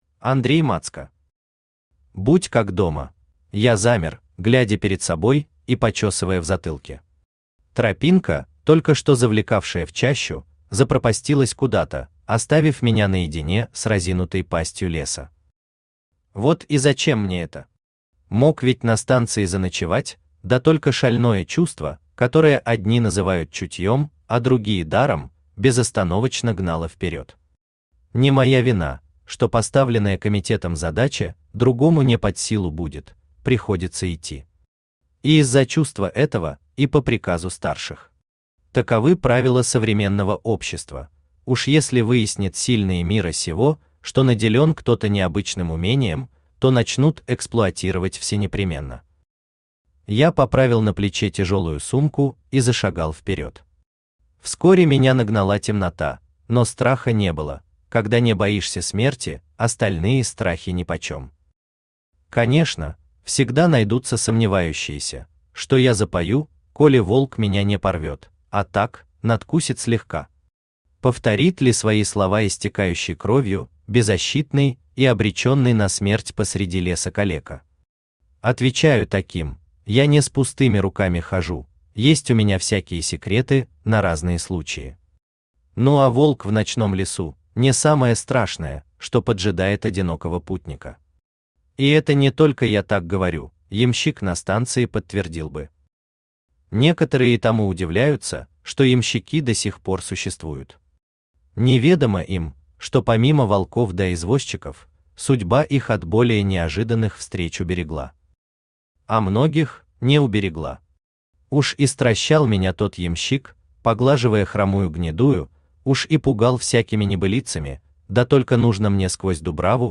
Aудиокнига Будь как дома Автор Андрей Мацко Читает аудиокнигу Авточтец ЛитРес.